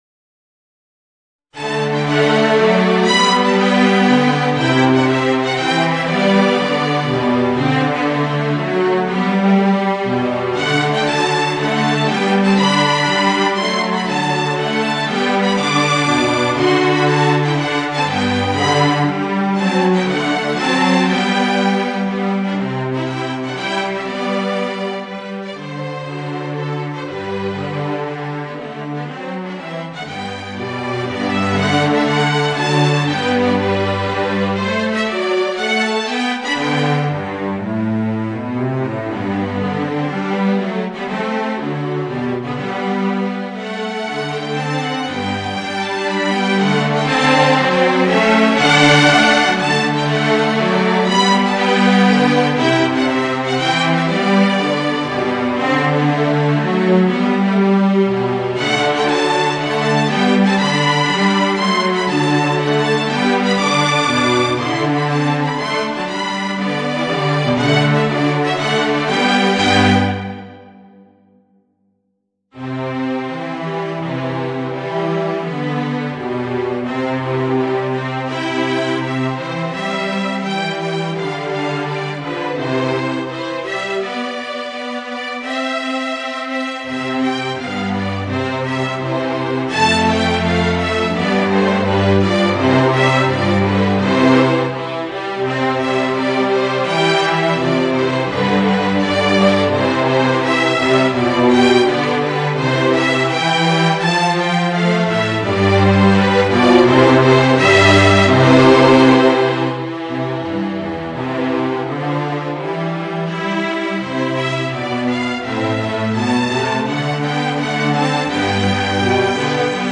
Gattung: Für 3 Saxophone
Für Alsaxophon, Ternorsaxophon und Baritonsaxophon